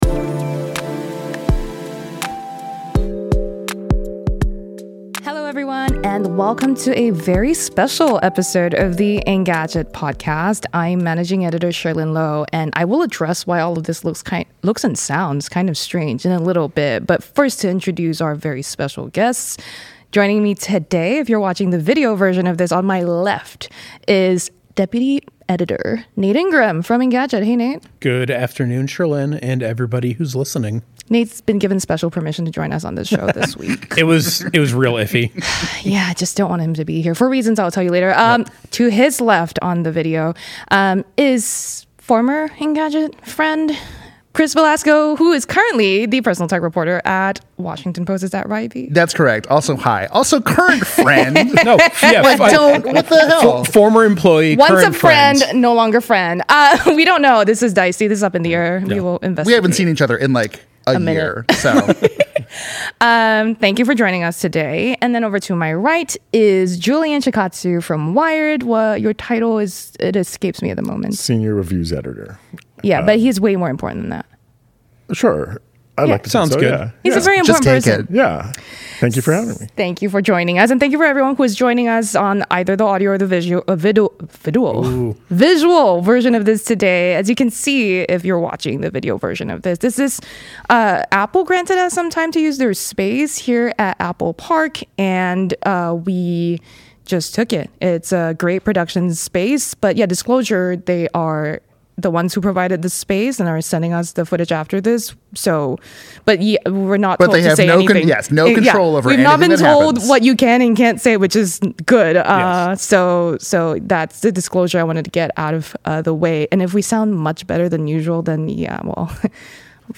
recorded live in Cupertino